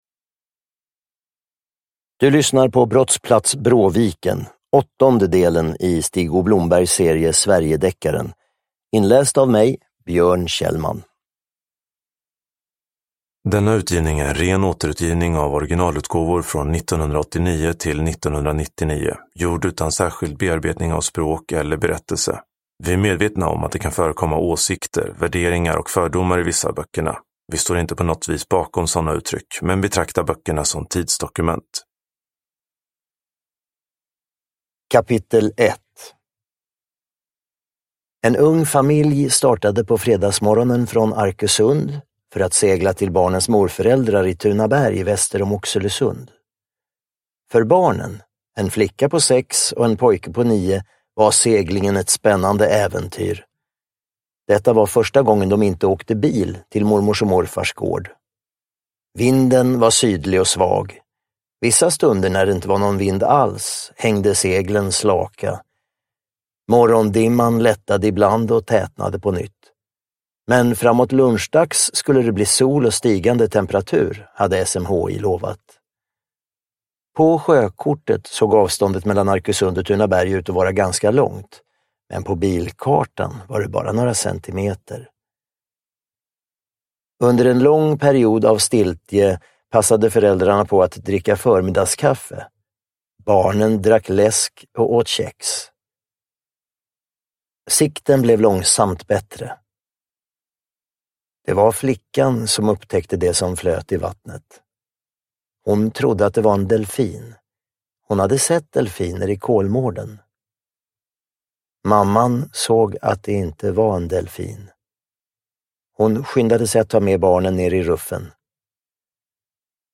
Brottsplats Bråviken – Ljudbok – Laddas ner
Uppläsare: Björn Kjellman